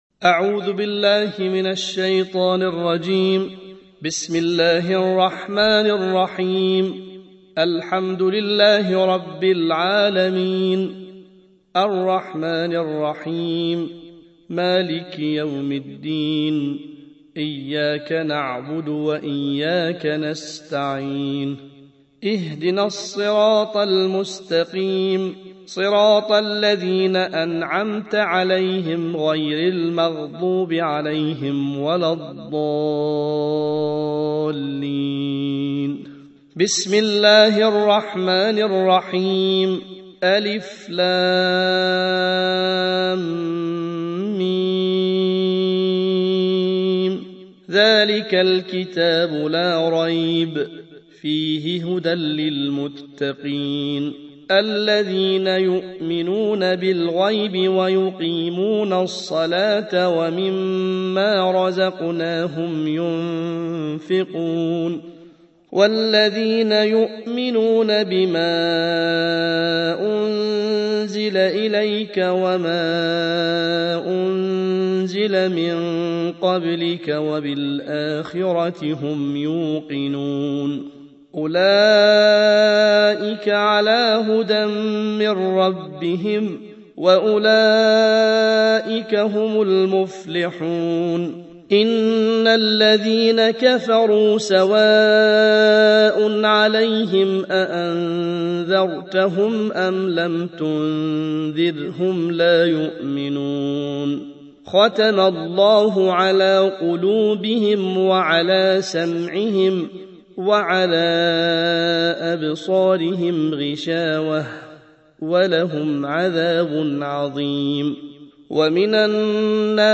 الجزء الأول / القارئ